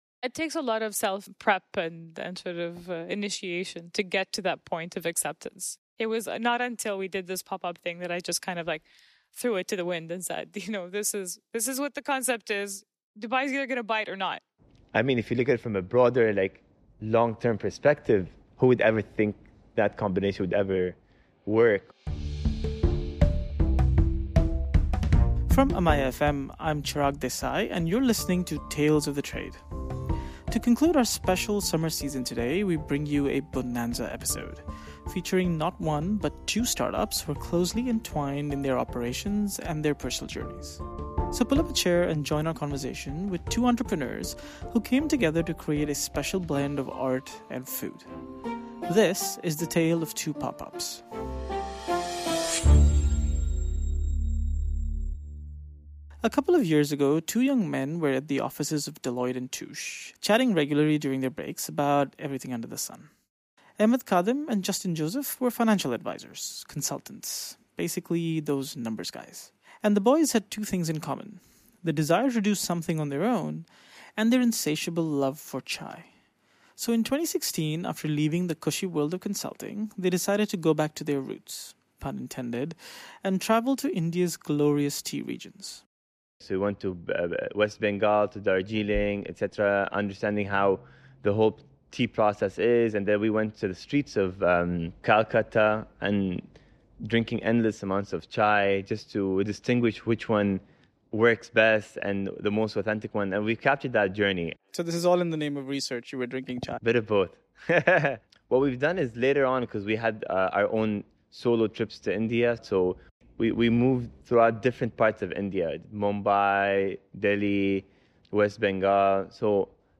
We conclude our summer season with a bonanza episode featuring not one, but two startups that are closely entwined in their operations & their personal journeys. So pull up a chair & join our conversation with two entrepreneurs who came together to create a special blend of art with food; the tale, of two pop ups.